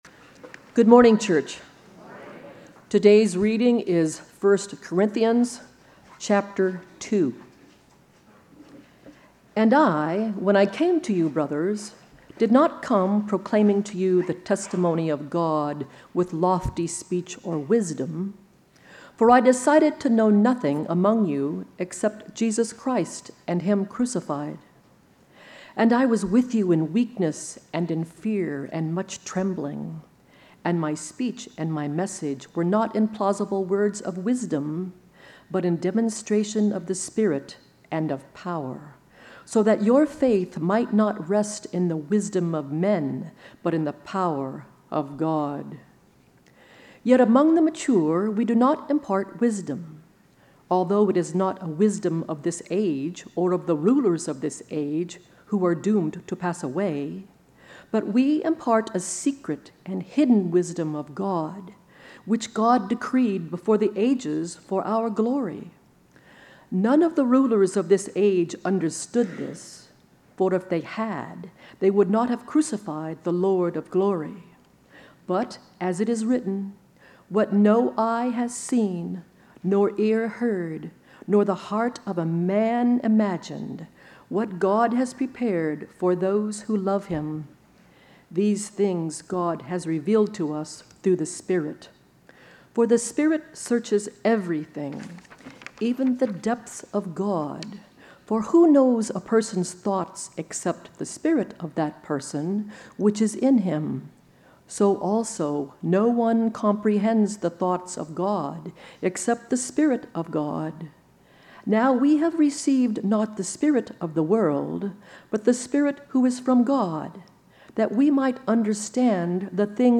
Sermon Notes: God's Wisdom is Greater Than Man's Clout